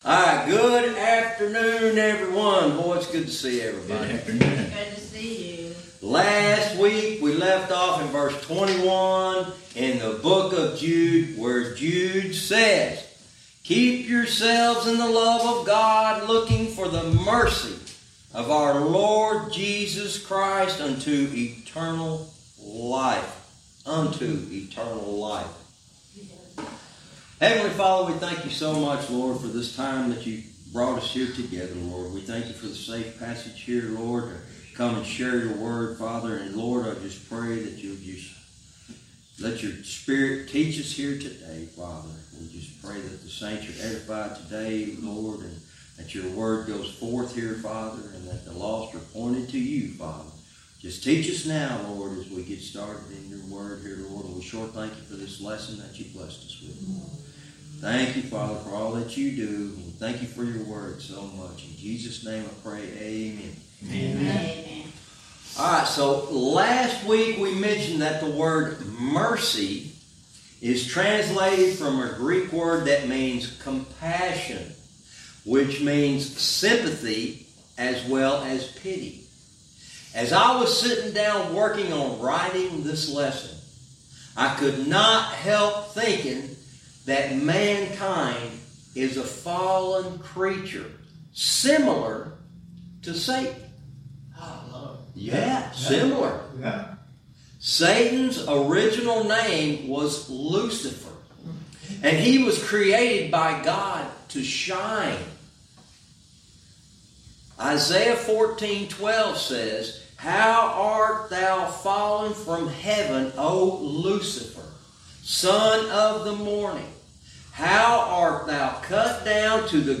Verse by verse teaching - Jude lesson 99 verse 21-22